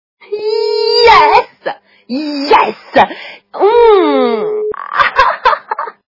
» Звуки » звуки для СМС » Женский голос - Yesa
При прослушивании Женский голос - Yesa качество понижено и присутствуют гудки.
Звук Женский голос - Yesa